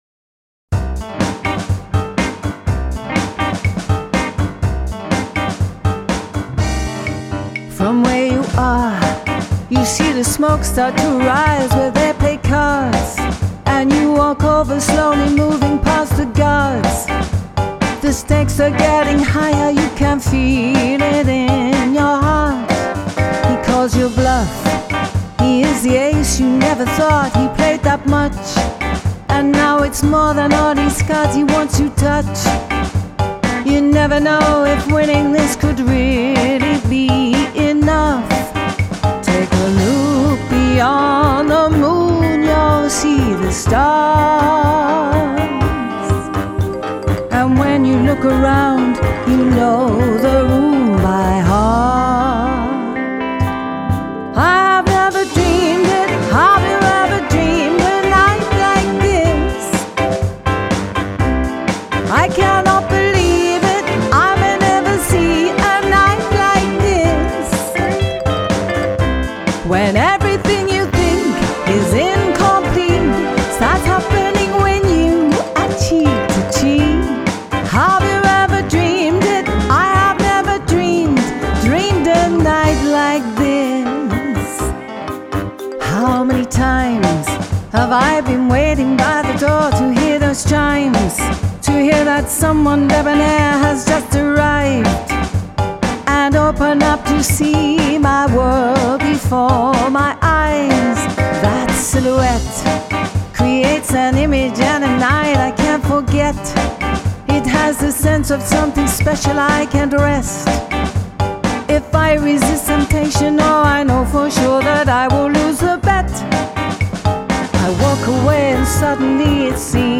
Function band